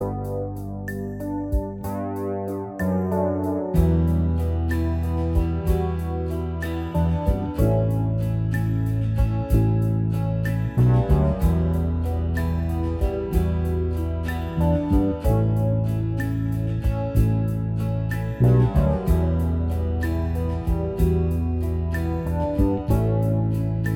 Minus Lead Guitar Indie / Alternative 5:20 Buy £1.50